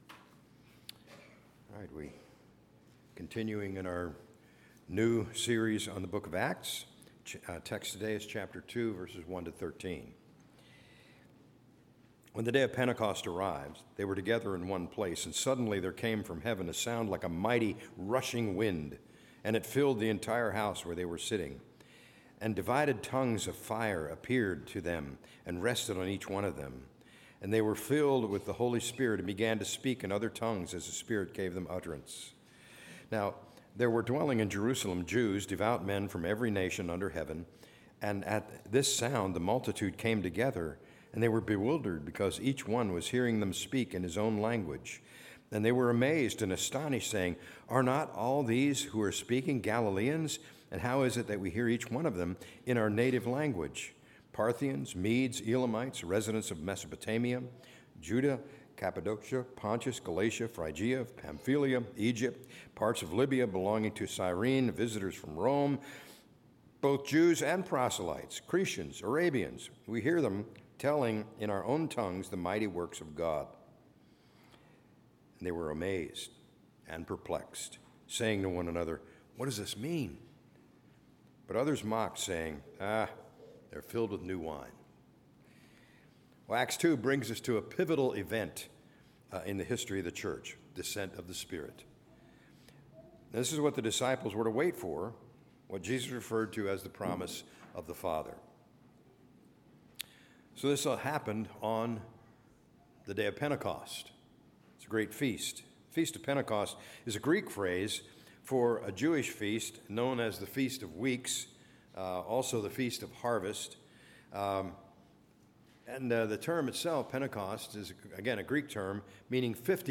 A message from the series "Acts 2025/26."